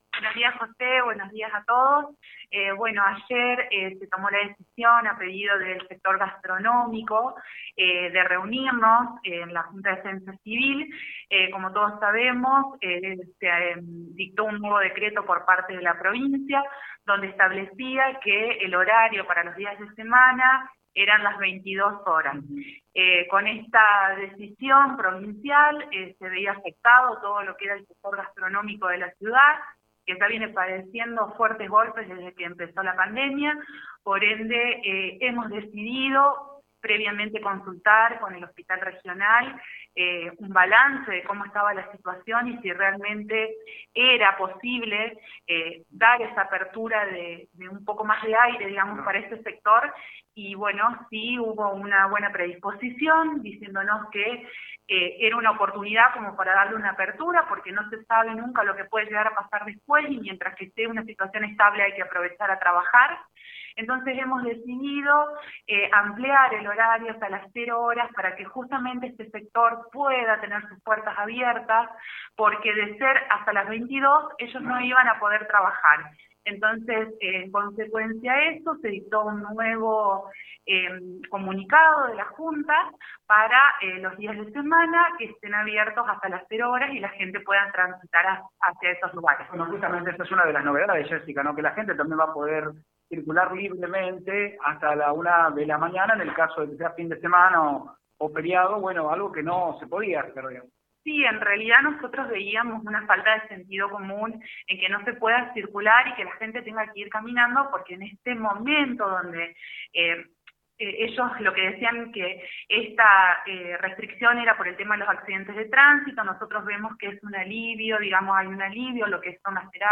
La Jueza de Faltas Jesica Beder explico sobre las nuevas medidas que regirán en Ceres.